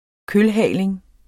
kølhaling substantiv, fælleskøn Bøjning -en, -er, -erne Udtale [ -ˌhæˀleŋ ] Oprindelse jævnfør kølhale Betydninger 1.